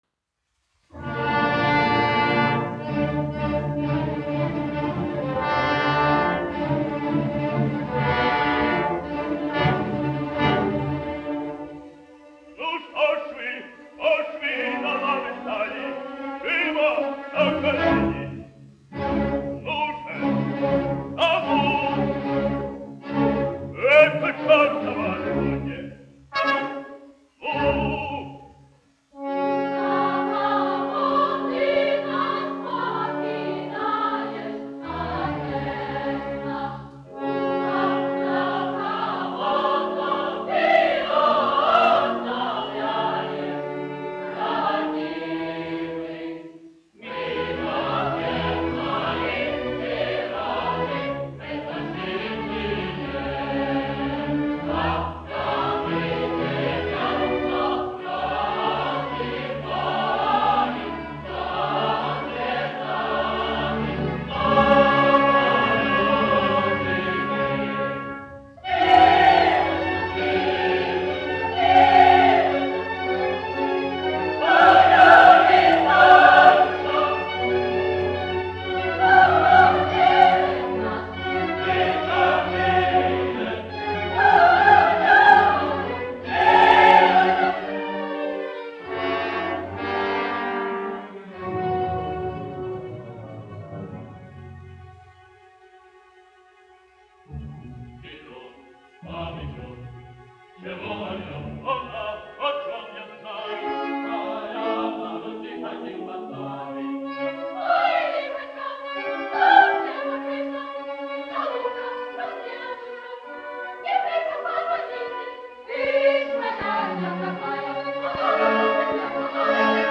1 skpl. : analogs, 78 apgr/min, mono ; 30 cm
Operas--Fragmenti
Latvijas vēsturiskie šellaka skaņuplašu ieraksti (Kolekcija)